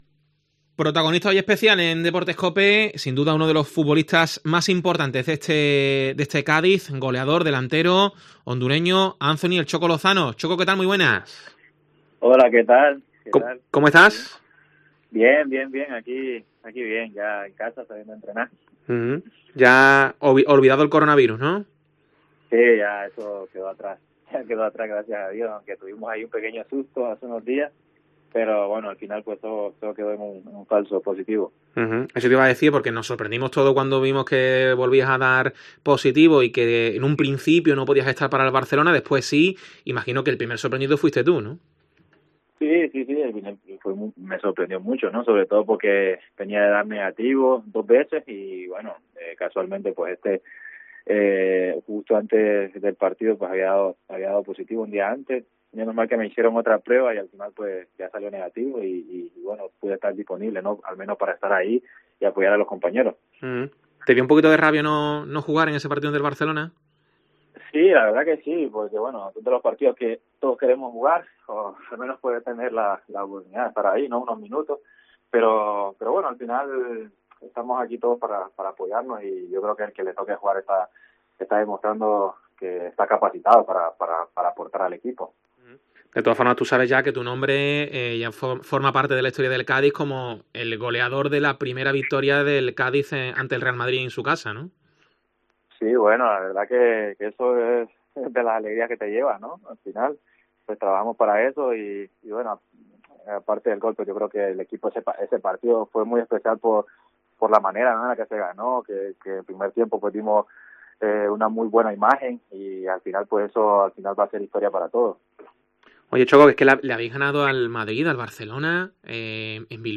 Choco Lozano en Deportes COPE Cádiz
El delantero amarillo ha pasado por los micrófonos de Deportes COPE Cádiz.